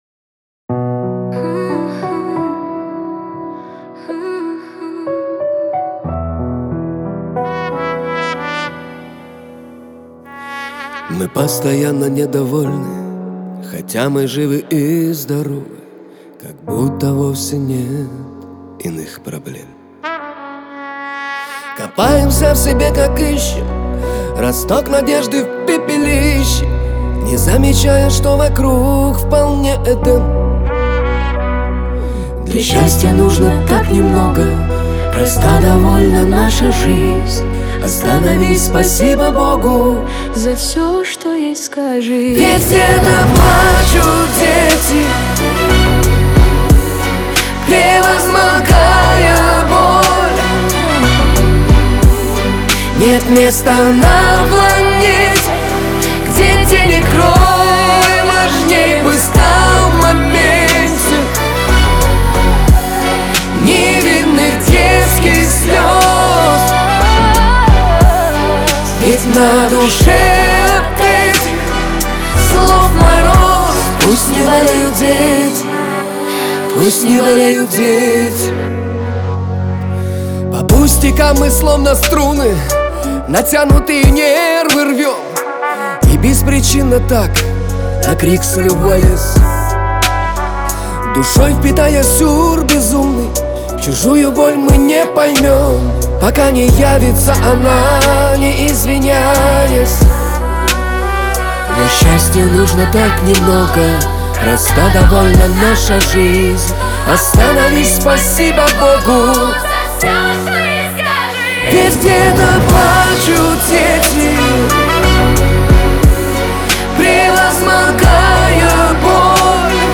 грусть
Лирика